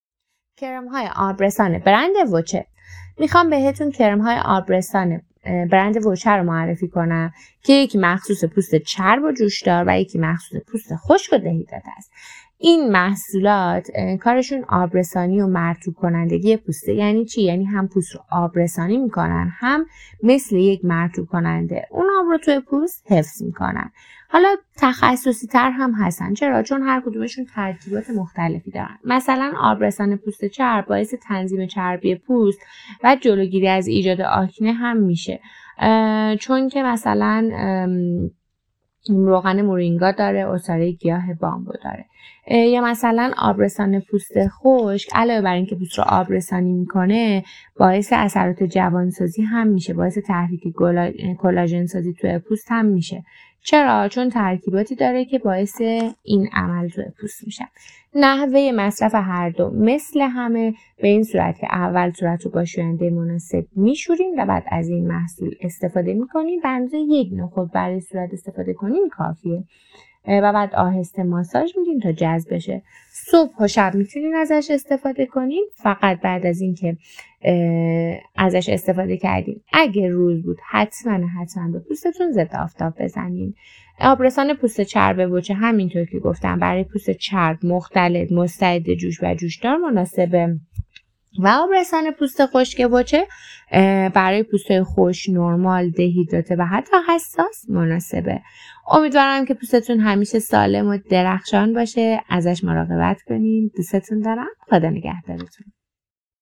معرفی صوتی آبرسان پوست چرب وچه